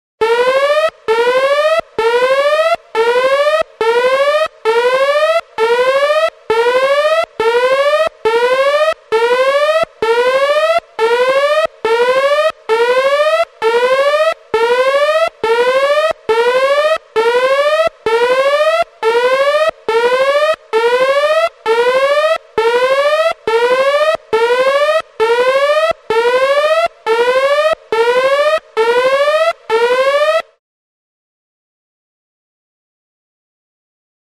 Alarm 2, Contemporary, Factory, - Science Lab - Spaceship Synthesized, Warning Signal, Whooper, Ascends In Pitch with Reverberant Slap Back.